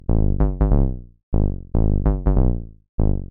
描述：这是在我的模拟模块化合成器上制作的一个短而甜的低音循环。它可用于许多电子流派，如Techno、Electro、Breakbeat、Dubstep，以及你能想到的任何其他类型。
Tag: 145 bpm Electronic Loops Bass Synth Loops 570.30 KB wav Key : G